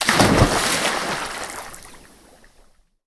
SnootGame/game/audio/effects/splash.ogg at master
splash.ogg